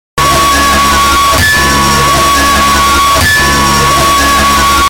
rên ahhhh ID ROBLOX AUDIO sound effects free download